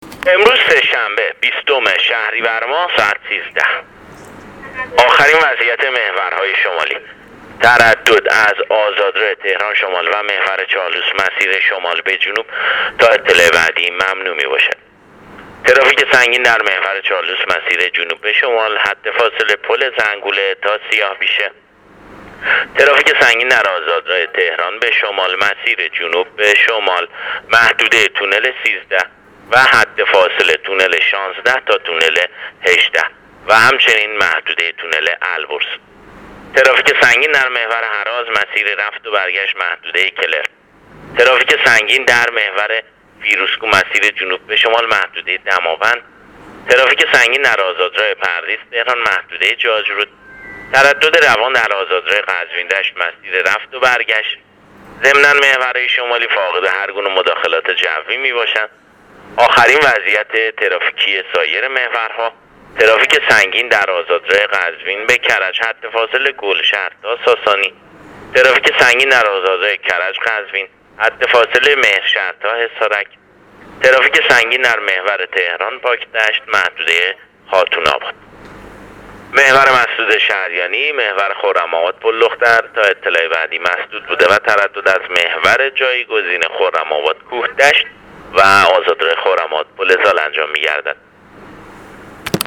گزارش رادیو اینترنتی از آخرین وضعیت ترافیکی جاده‌ها تا ساعت ۱۳ بیستم شهریور؛